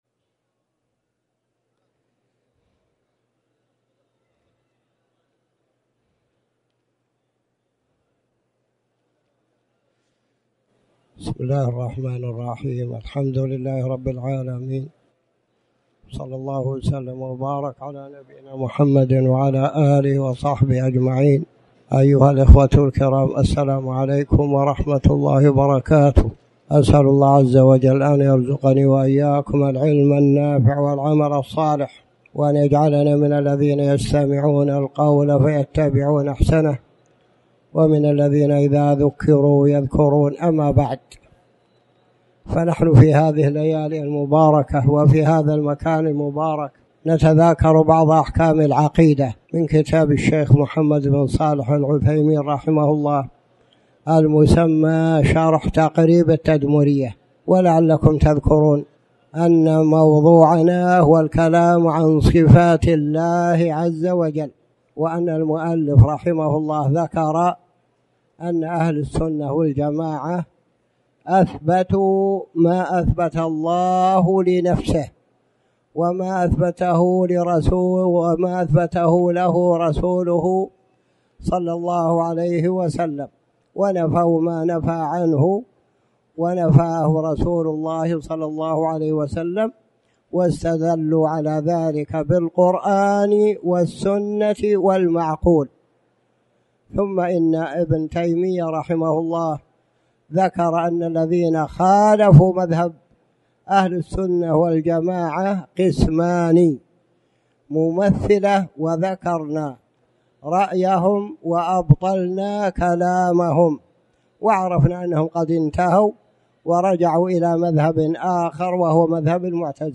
تاريخ النشر ١١ ذو القعدة ١٤٣٩ هـ المكان: المسجد الحرام الشيخ